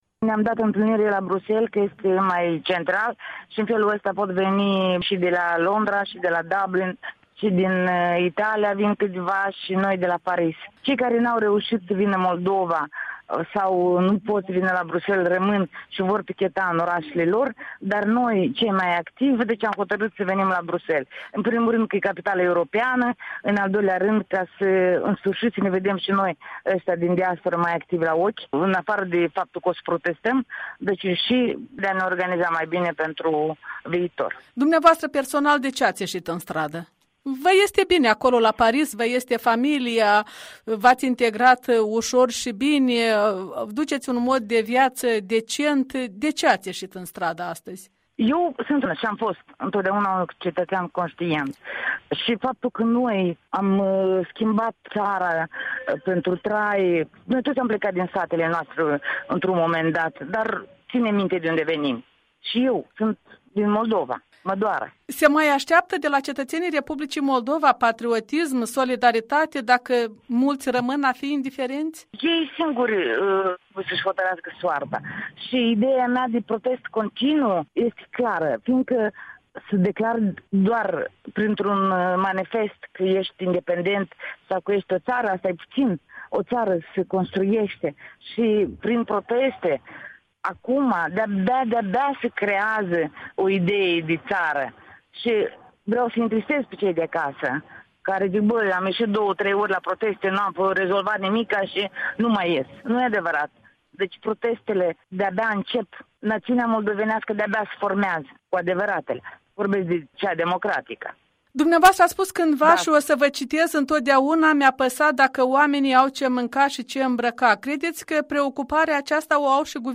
Dialog cu o moldoveancă stabilită la Paris, dar care a protestat duminică la Bruxelles.